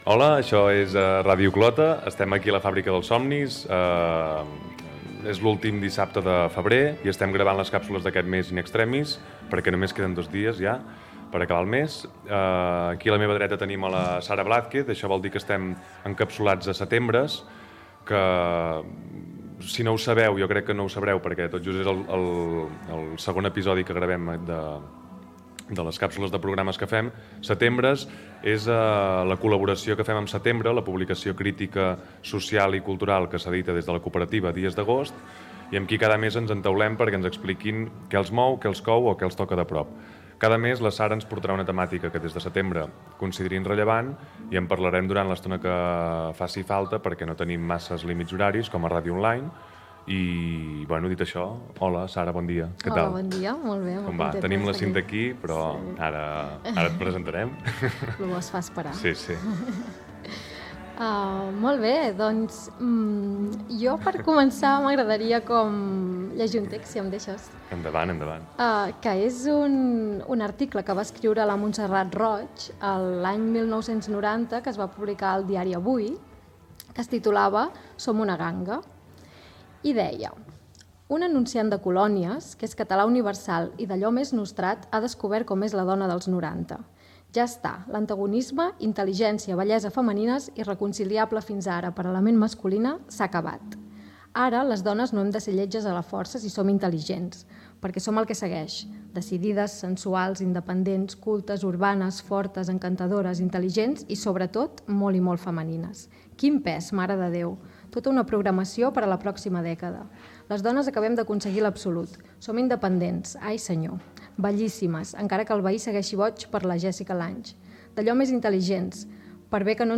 Presentació del programa amb la identificació de l'emissora
Gènere radiofònic Informatiu